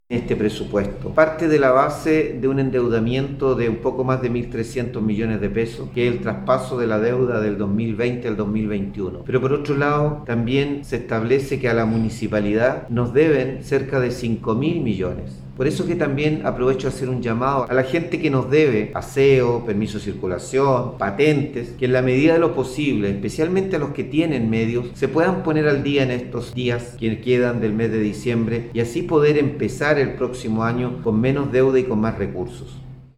02-ALCALDE-MELLA-Deuda-municipal-y-de-la-comunidad.mp3